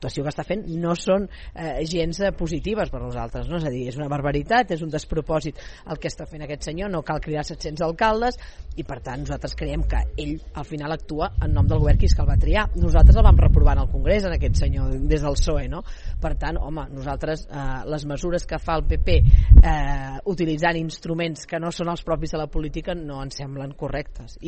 En una entrevista a l’Info Vespre de Ràdio Calella TV, la diputada del PSC va sortir en defensa de la posició dels socialistes espanyols i del seu líder, Pedro Sánchez.